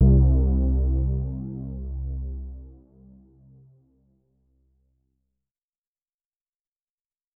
Gutta808-2.wav